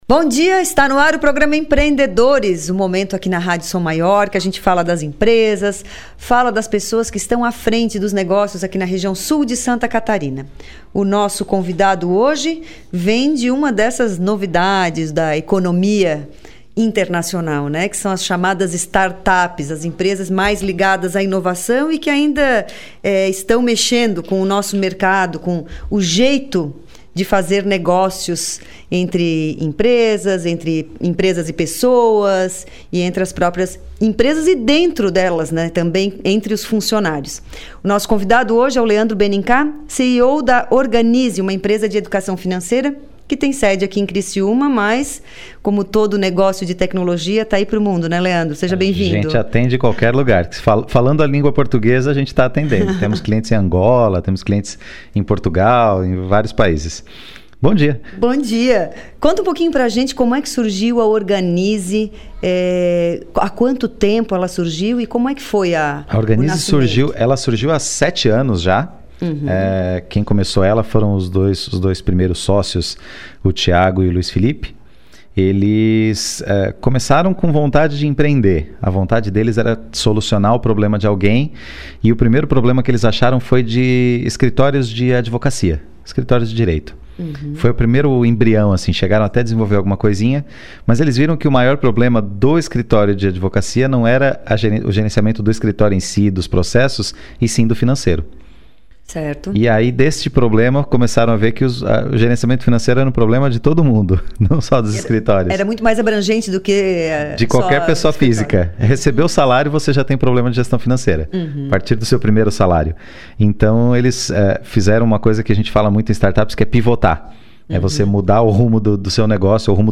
Entrevista
O Programa Empreendedores é veiculado originalmente na Rádio Som Maior.